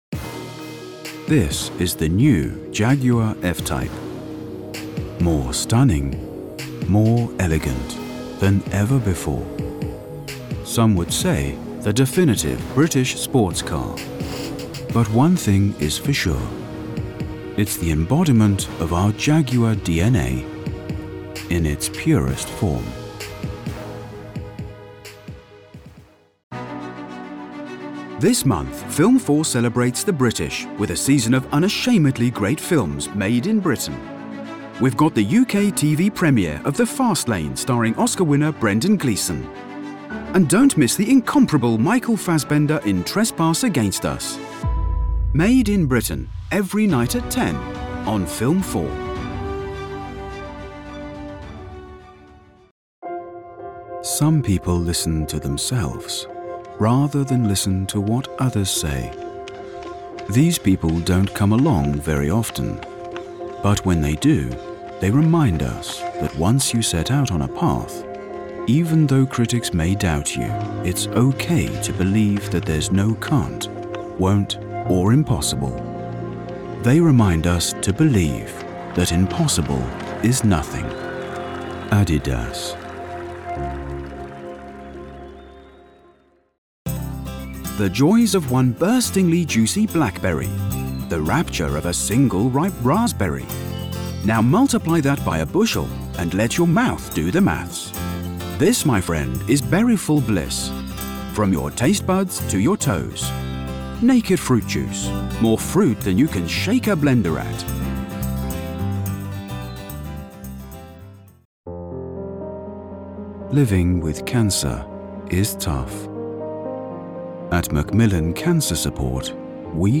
Inglés (Británico)
Profundo, Natural, Seguro, Amable, Cálida
Corporativo